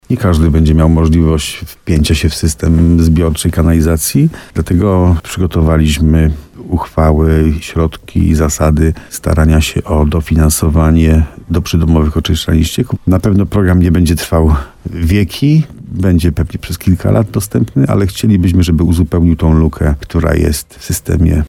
Jak mówi wójt Benedykt Węgrzyn to wsparcie dla tych osób, którzy mieszkają w miejscach, gdzie nie ma możliwości poprowadzenia sieci kanalizacyjnej.